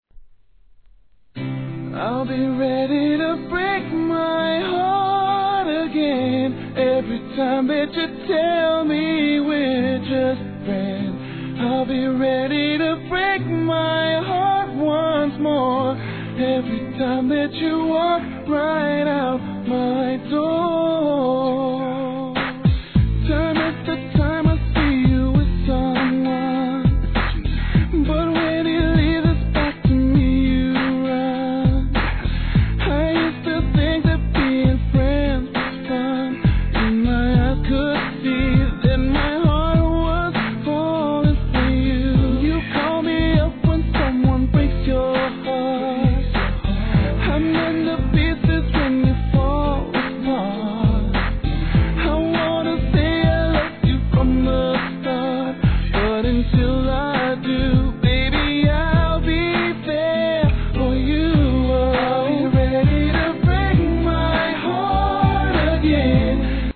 HIP HOP/R&B
透き通るような優しいヴォーカルで歌う強い恋心♪1998年の素晴らしいEU産R&B!